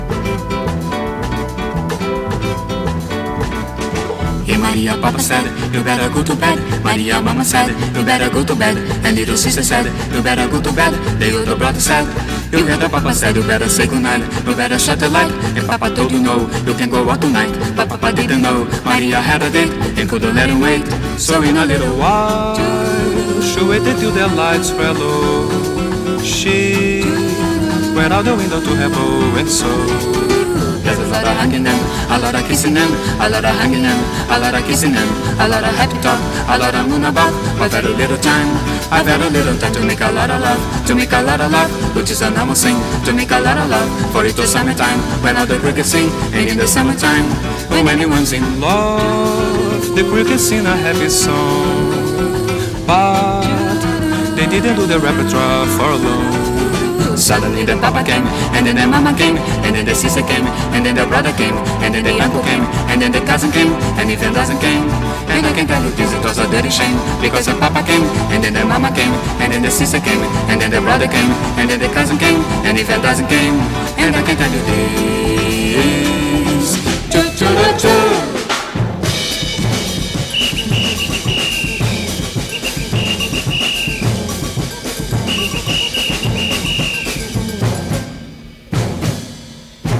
WORLD / BRAZIL / FREE SOUL